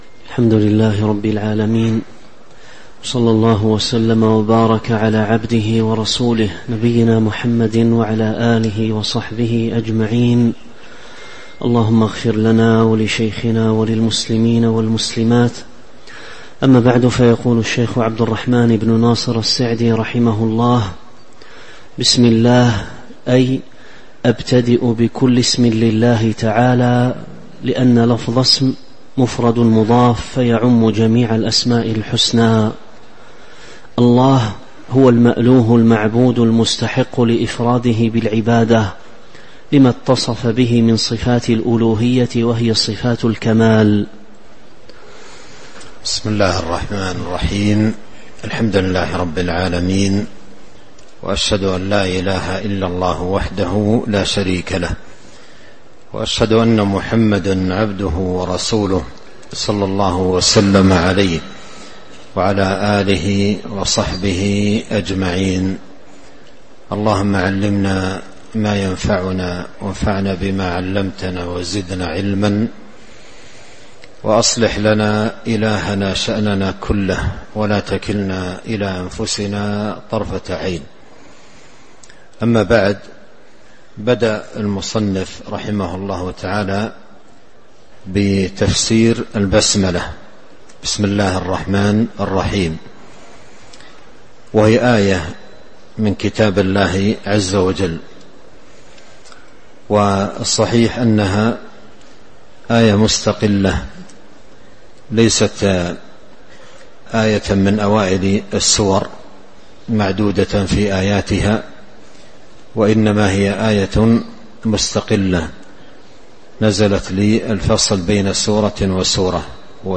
تاريخ النشر ١٤ ربيع الأول ١٤٤٦ هـ المكان: المسجد النبوي الشيخ